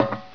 pl_metal4.wav